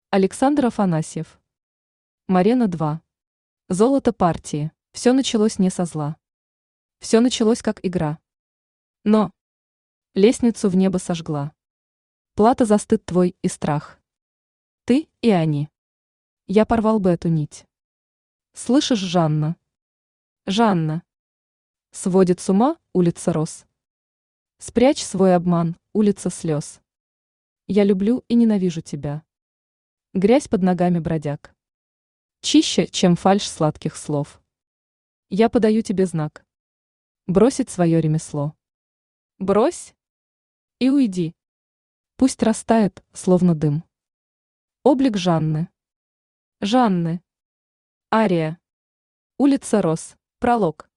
Аудиокнига Морена-2. Золото партии | Библиотека аудиокниг
Золото партии Автор Александр Афанасьев Читает аудиокнигу Авточтец ЛитРес.